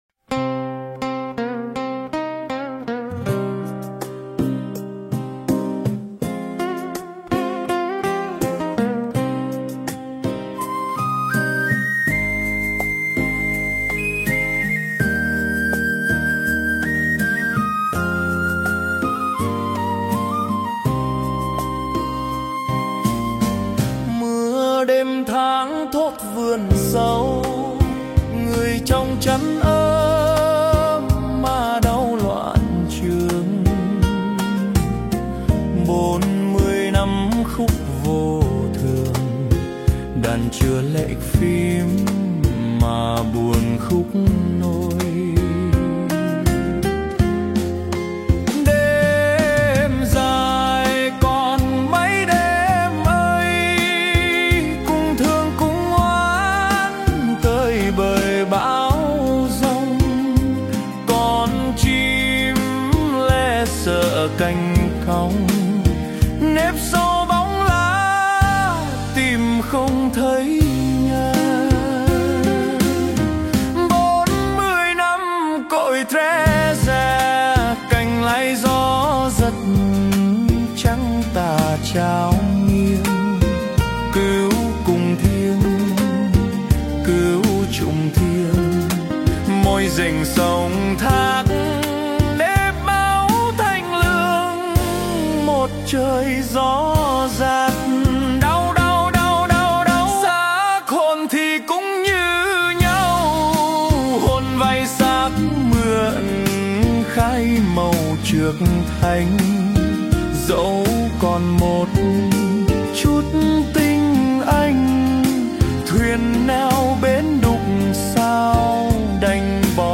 Phổ nhạc: Suno AI